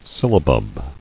[sil·la·bub]